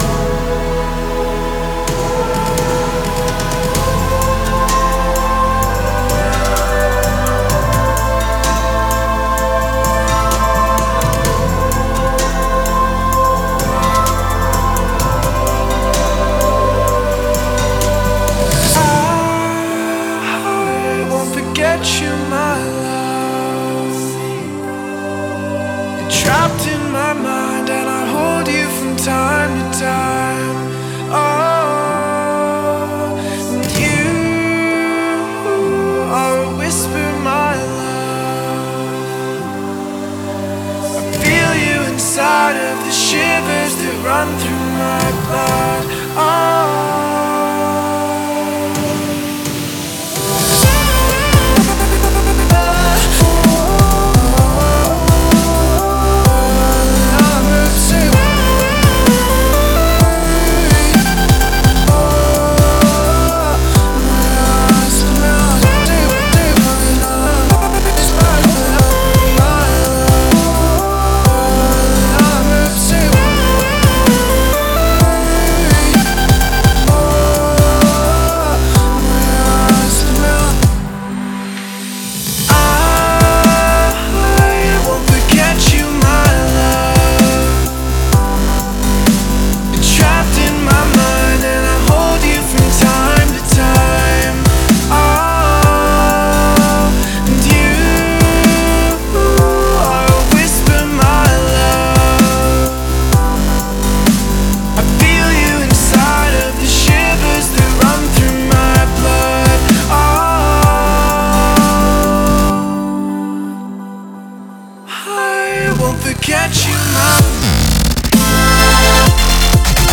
Категория: Электро музыка » Дабстеп